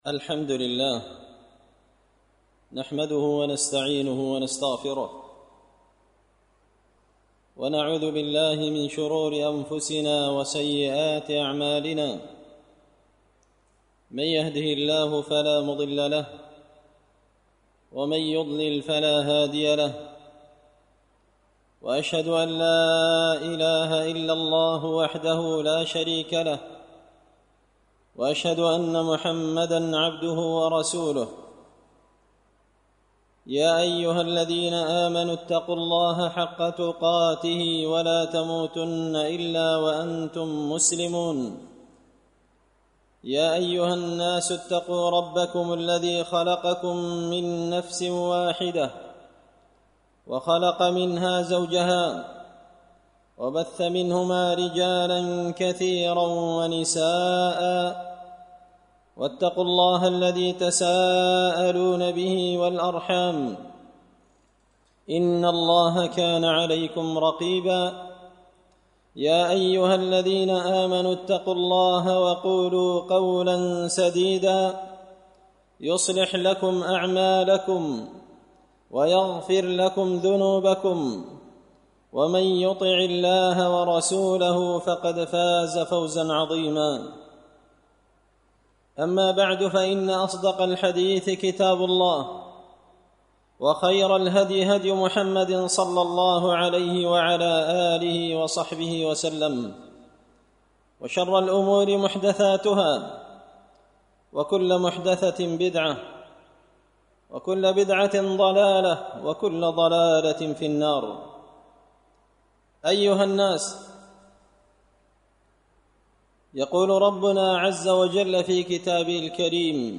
خطبة جمعة بعنوان – الهمة العالية في طلب الاخرة
دار الحديث بمسجد الفرقان ـ قشن ـ المهرة ـ اليمن